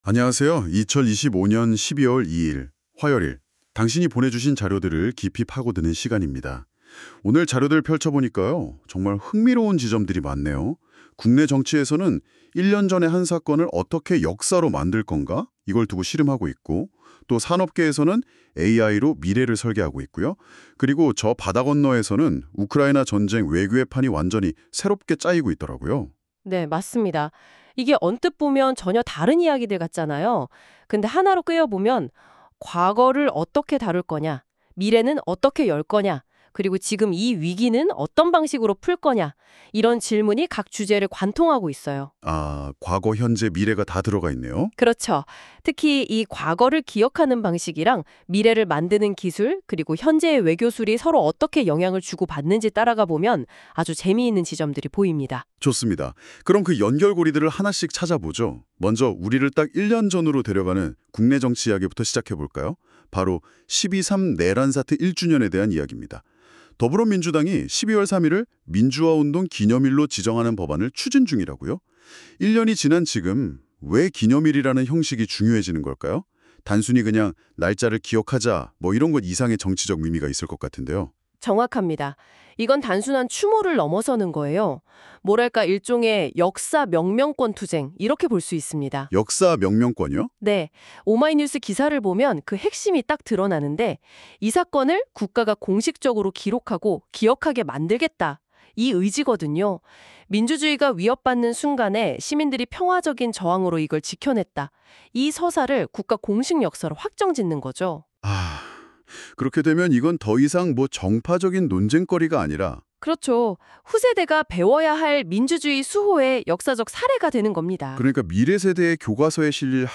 구글 노트북 LM으로 생성했습니다.